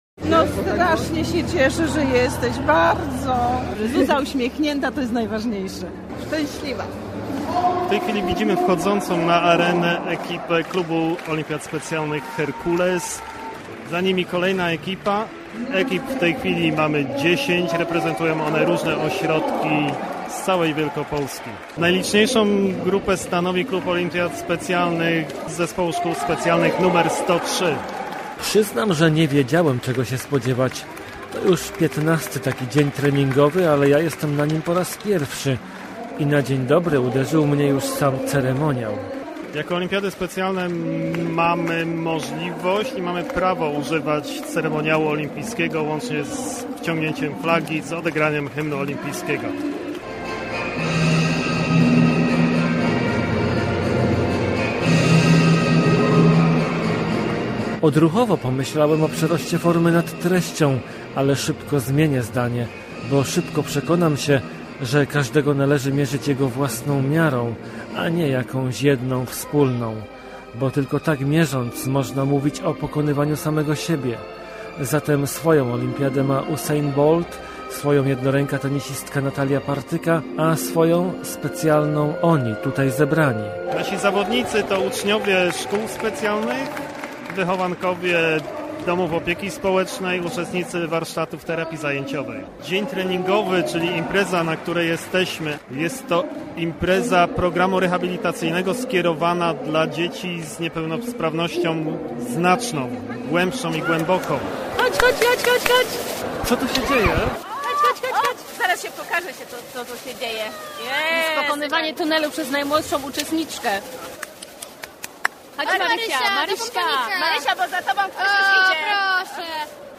Jeden taki dzień - reportaż